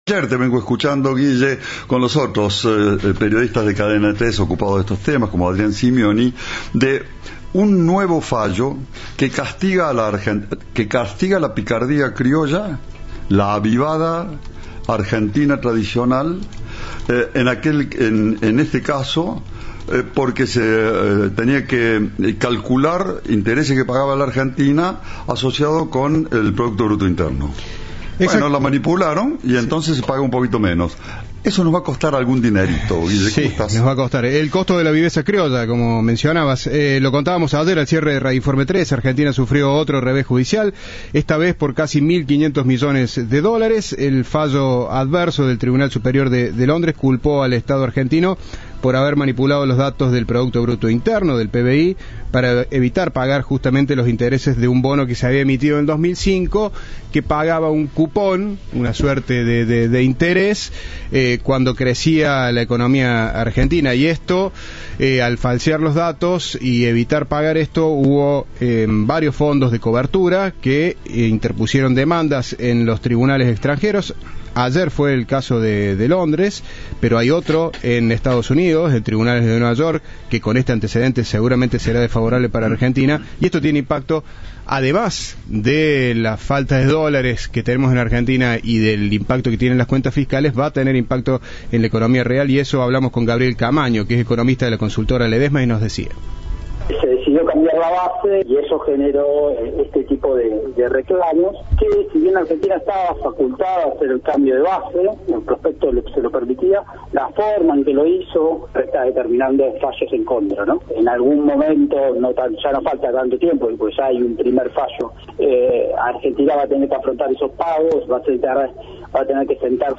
En diálogo con Cadena 3, el economista
Informe